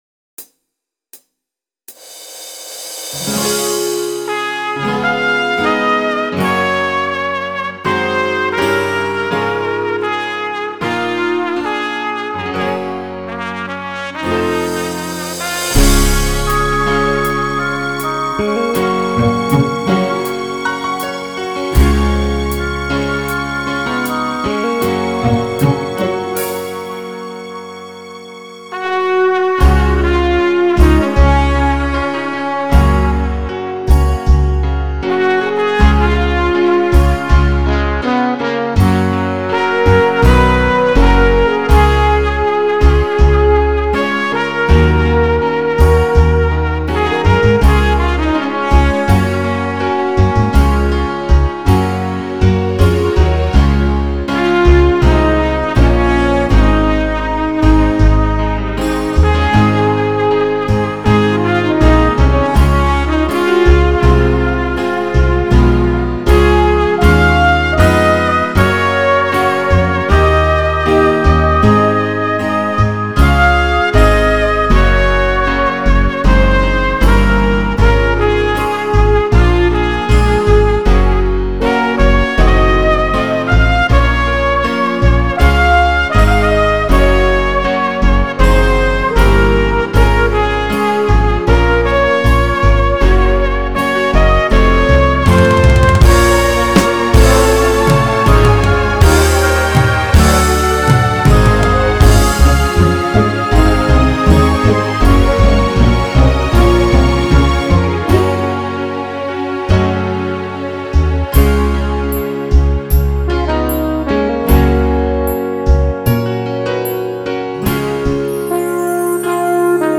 th296HFTUD  Download Instrumental